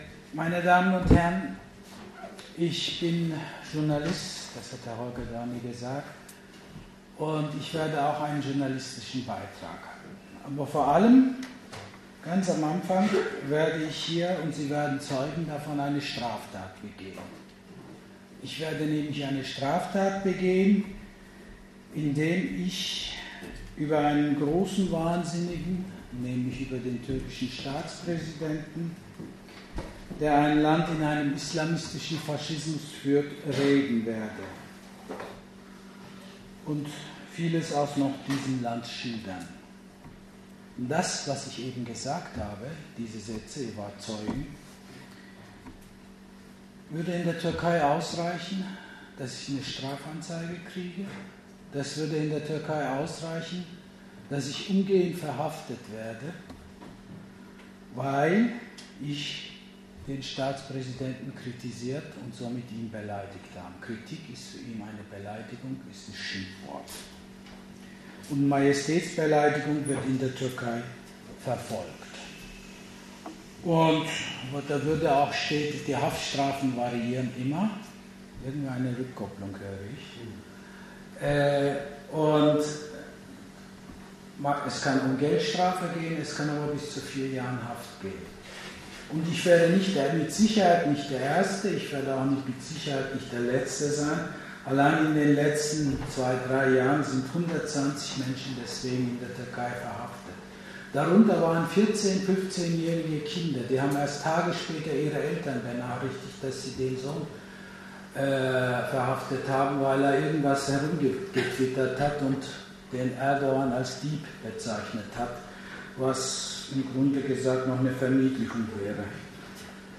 Wo: Humboldt Universität zu Berlin, Unter den Linden 6, Raum 2097